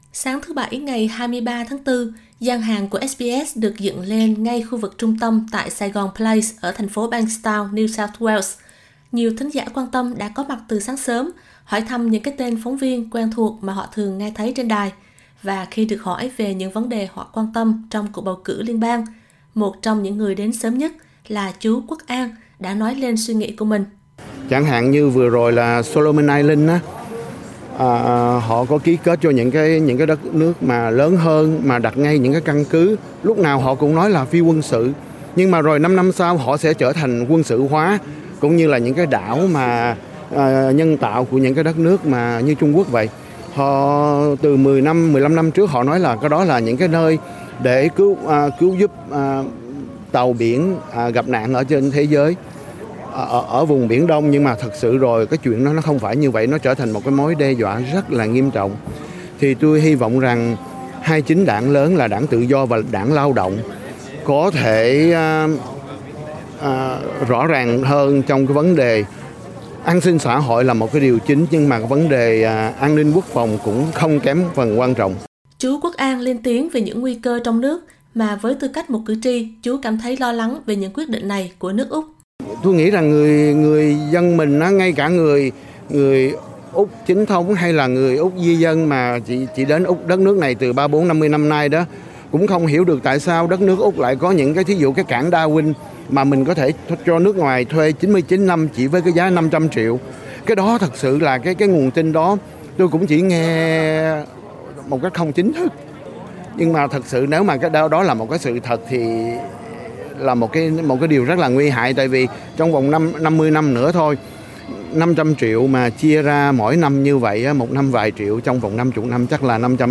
Trong buổi SBS gặp mặt ứng cử viên và đại diện cộng đồng nhằm đối thoại về Bầu cử Liên bang 2022, vào thứ Bảy 23/4, các thính giả của đài Tiếng Việt đã ghé thăm gian hàng và trò chuyện về bầu cử. Sau đây là những ý kiến tiêu biểu của một vài cư dân gốc Việt ở Bankstown về những vấn đề họ quan tâm cho địa phương cũng như với nước Úc.